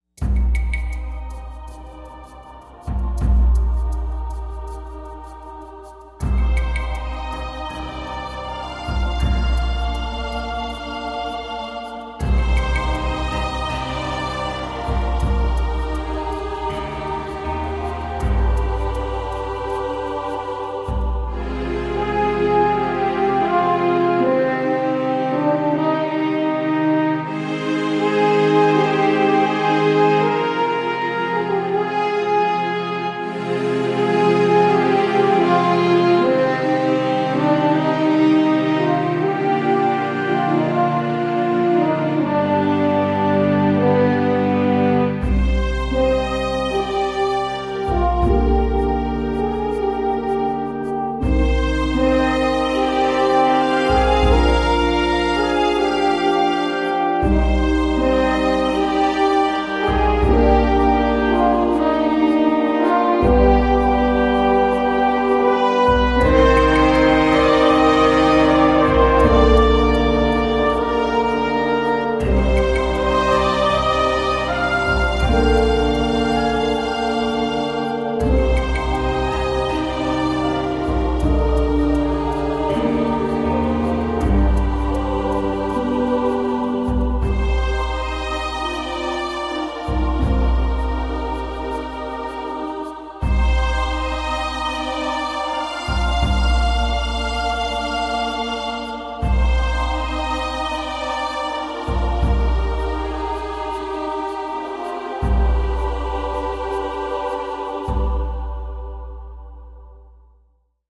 Main theme/menu music